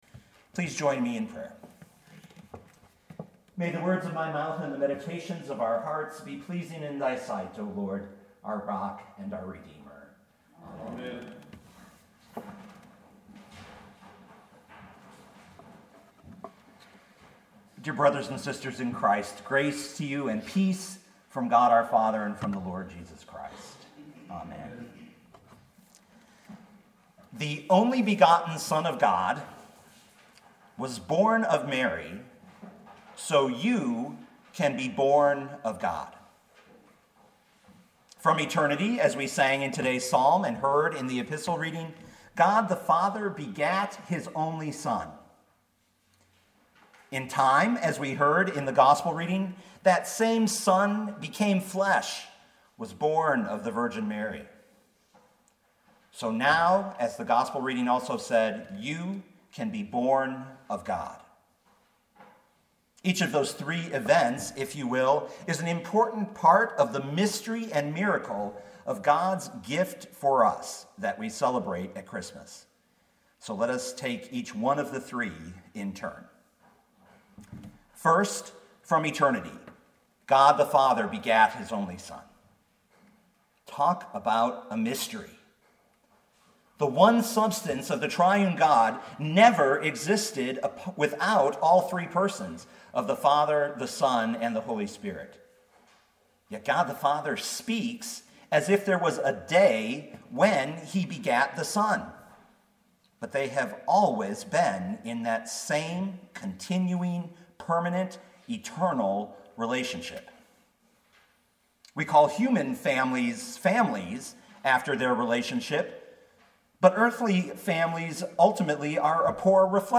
2018 John 1:1-18 Listen to the sermon with the player below, or, download the audio.